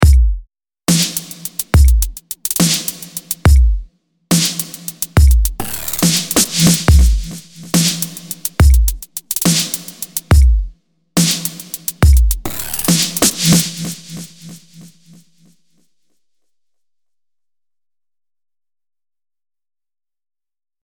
I followed this up by adding a high pass filtered delay effect to the snare fills.
The phaser and delay are in place